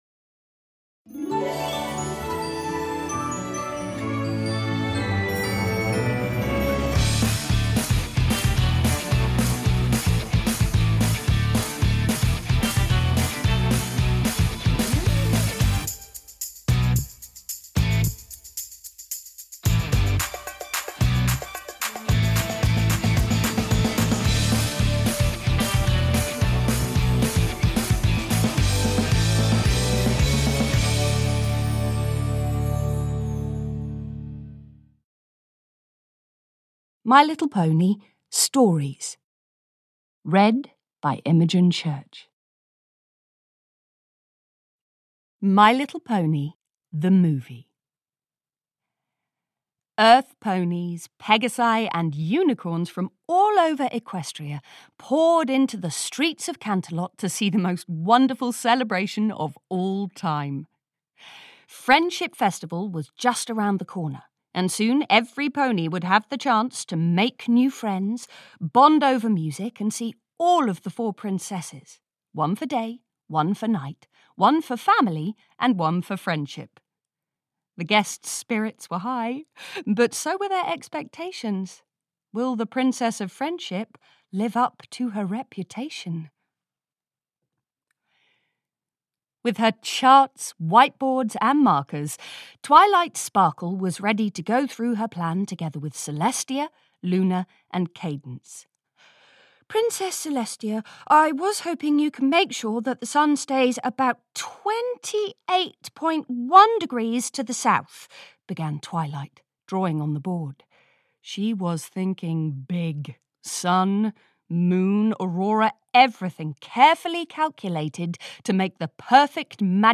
My Little Pony: Stories (EN) audiokniha
Ukázka z knihy